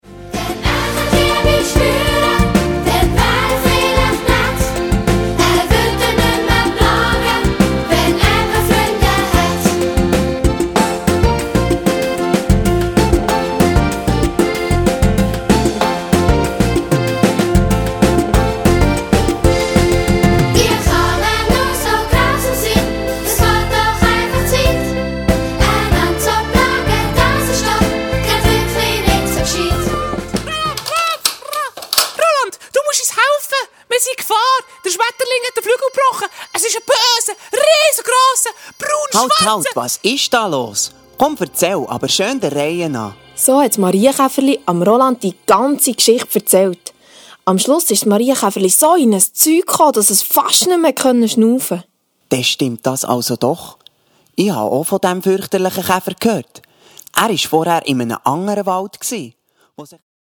Minimusical für Grundschule und 1./2. Klasse